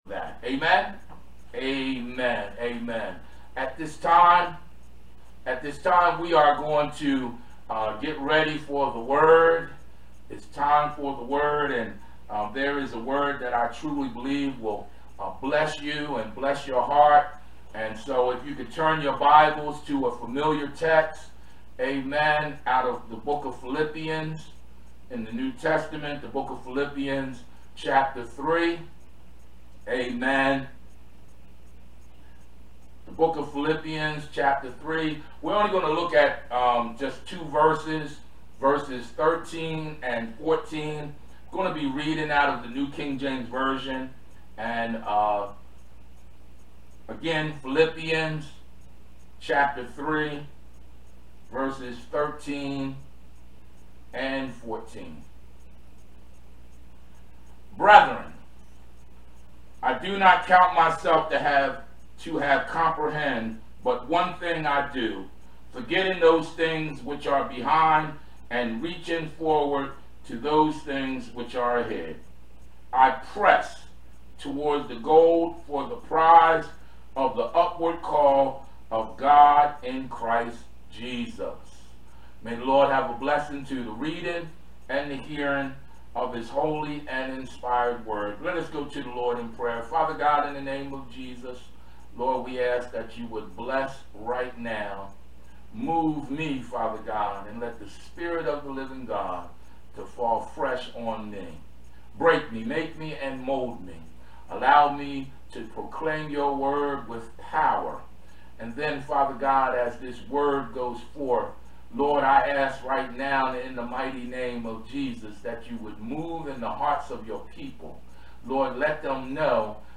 Sermons | Macedonia Baptist Church